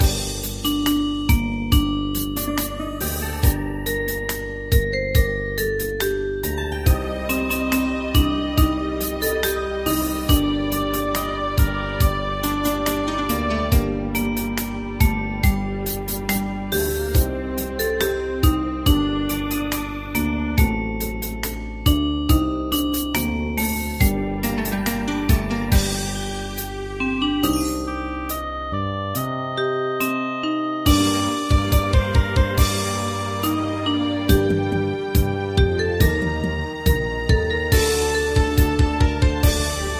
大正琴の「楽譜、練習用の音」データのセットをダウンロードで『すぐに』お届け！
Unison musical score and practice for data.